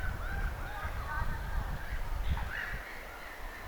ihmisen tekemältä ääneltä.
mika_laji_1_tietaakseni_ei_ihmisen_tekema_eika_se_aanikuvaajassa_silta_vaikutakaan.mp3